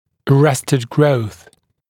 [ə’restəd grəuθ][э’рэстэд гроус]остановка роста, прекращение роста, задержанный рост (под влиянием каких-либо воздействующих факторов)